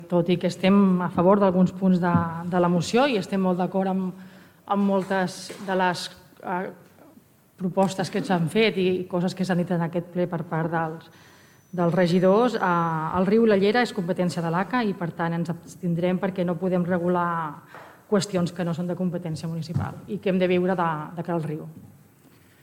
Aquest és un dels acords al qual s’ha arribat al ple de l’Ajuntament de Tordera.
Ho detalla la regidora Nàdia Cantero: